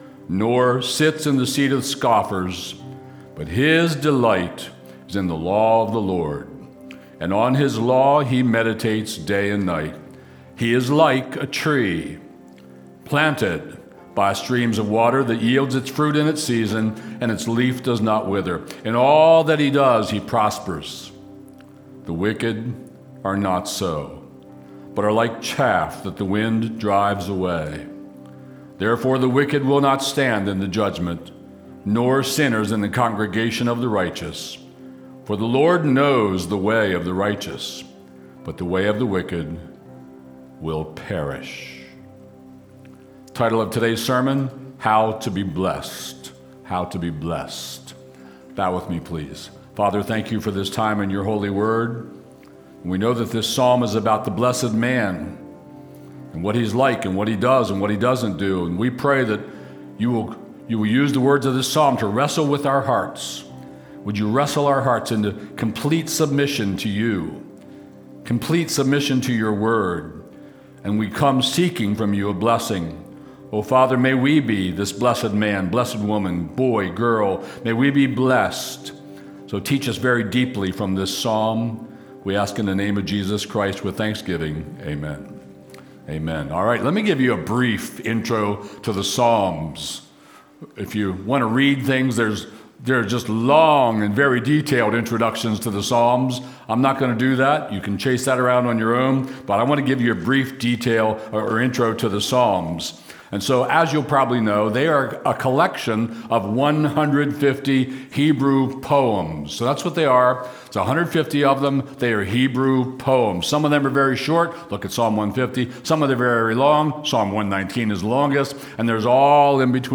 Stream Sermons from Cornerstone Harford County